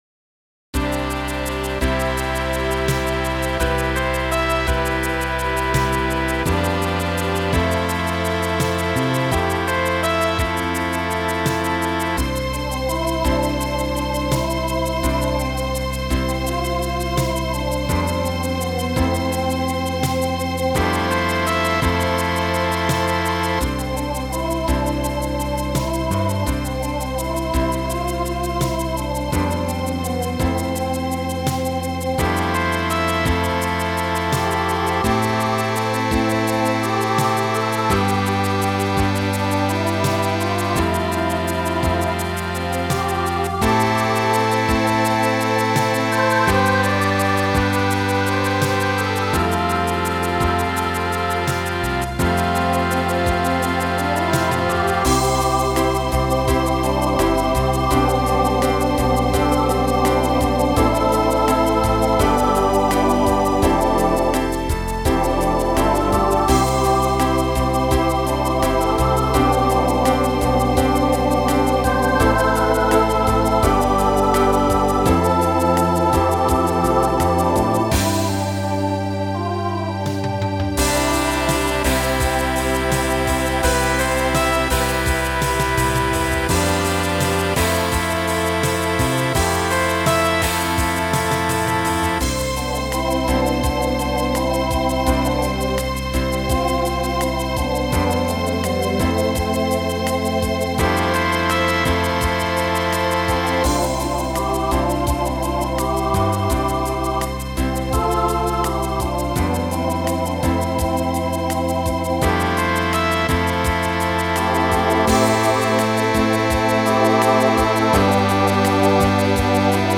Voicing SATB Instrumental combo Genre Country
Function Ballad